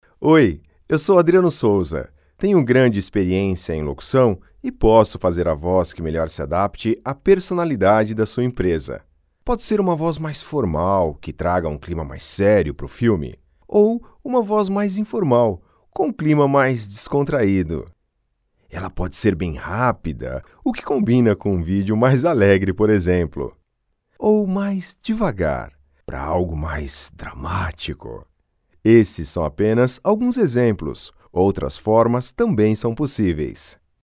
Voz Madura, Confiante, Natural, SimpĂĄtica, Acolhedora, Segura, Jovem, Coloquial, Conversada, Caricata.
Sprechprobe: Sonstiges (Muttersprache):